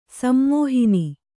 ♪ sammōhini